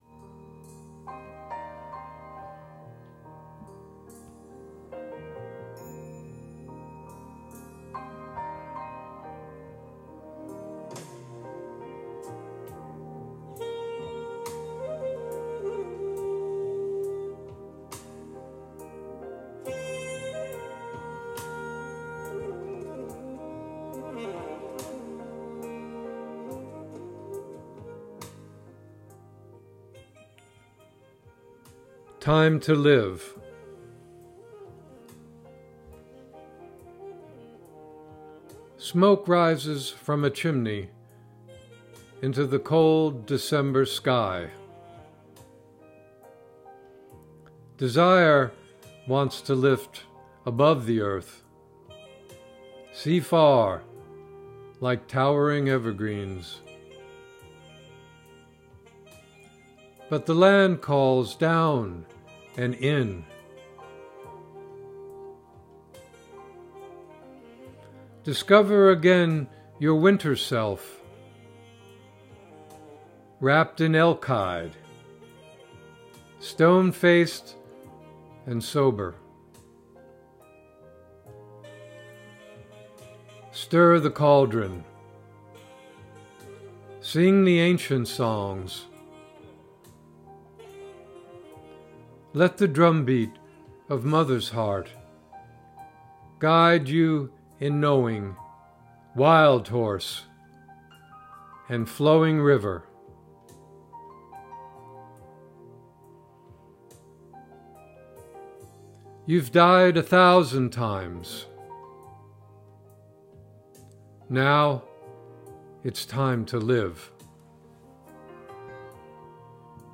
Reading of “Time to Live” with music by Van Morrison